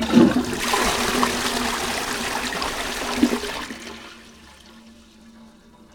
flush.ogg